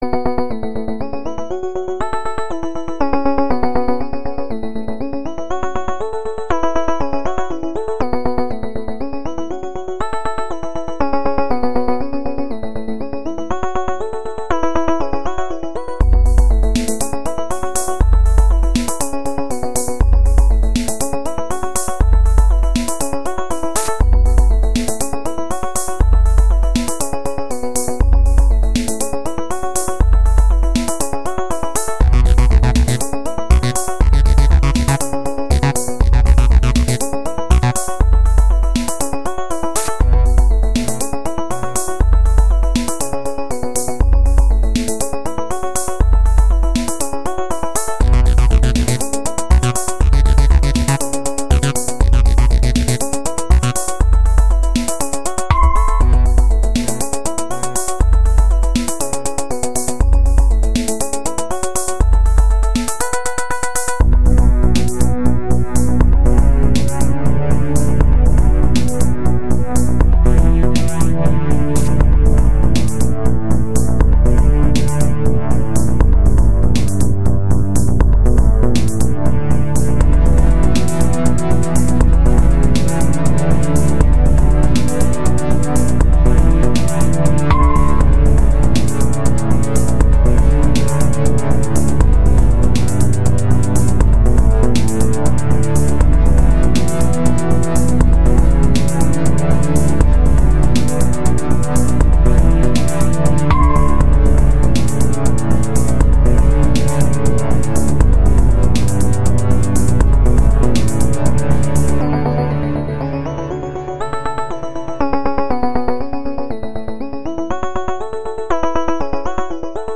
Basic calm song.
But every time the deep beat occurs, it overpowers and muffles the other instruments.
Cool tune nonetheless.